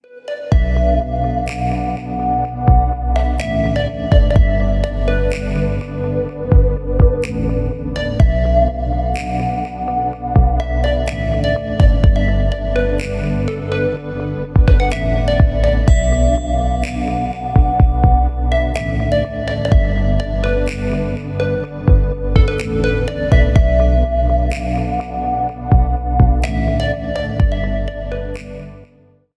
Ремикс # Поп Музыка
без слов # спокойные